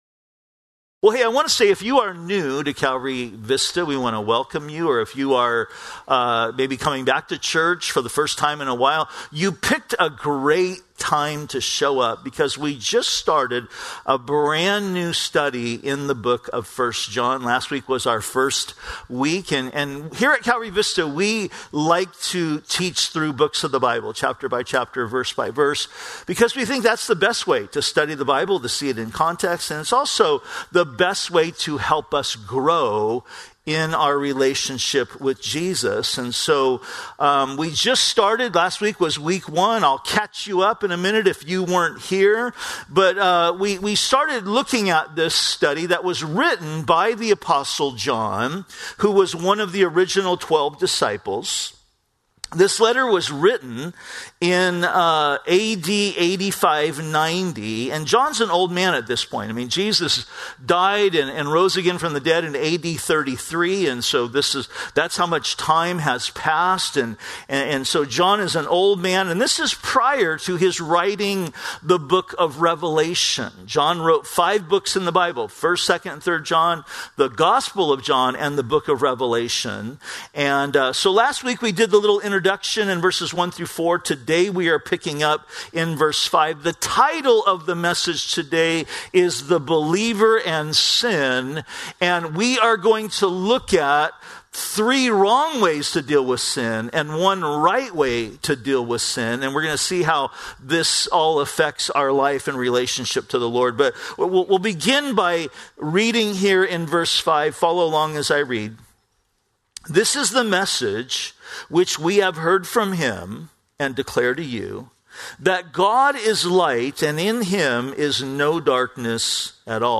# Religion